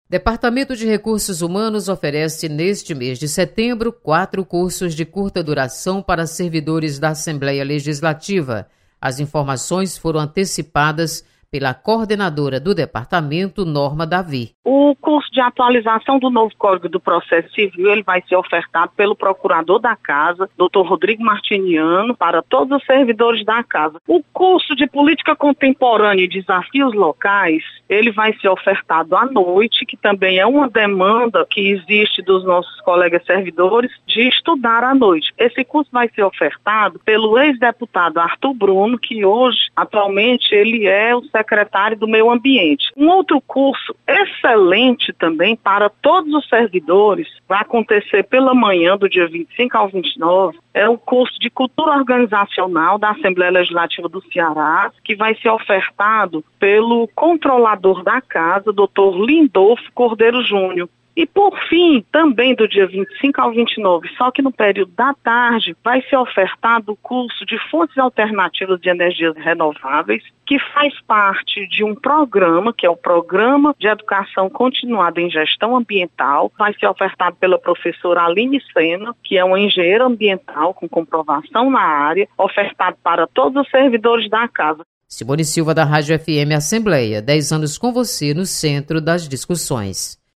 Novos cursos para servidores da Assembleia Legislativa são ofertados pelo Departamento de Recursos Humanos. Repórter